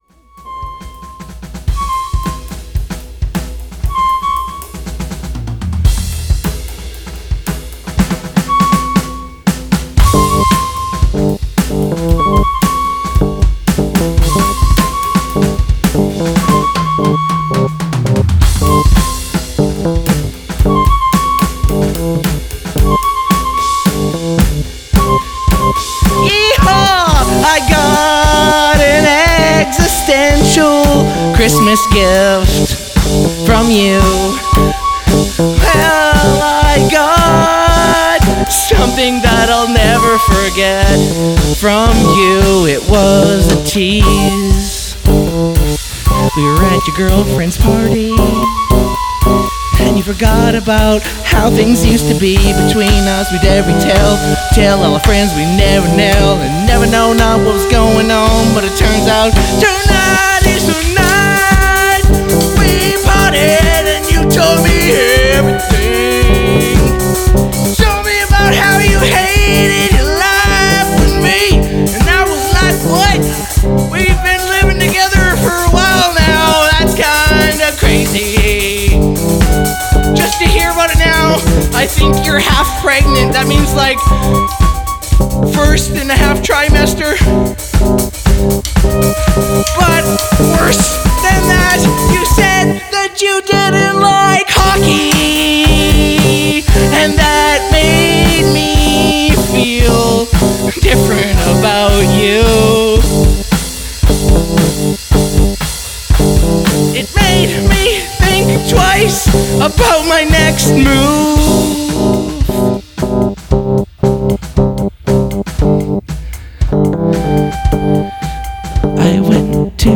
Alternative Rock
Synthesizer
Drums
Main Vocals
Woodwind